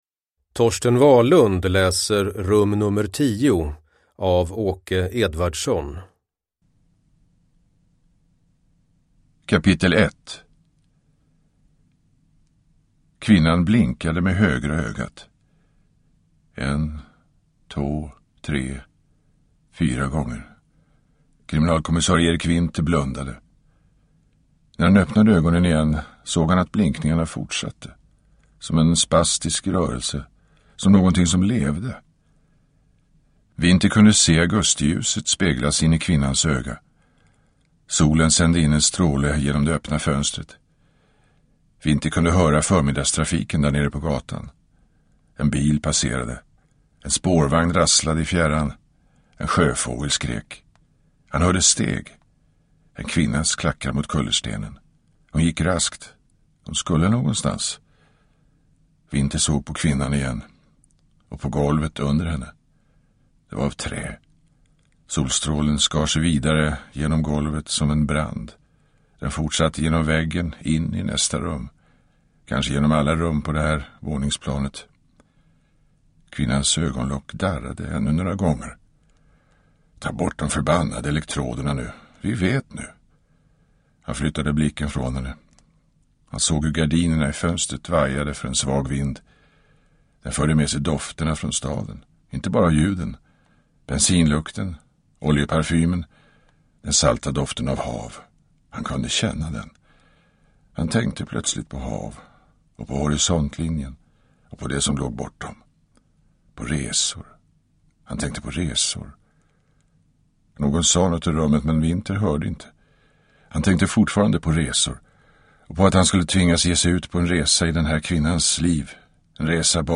Rum nummer 10 – Ljudbok – Laddas ner
Uppläsare: Torsten Wahlund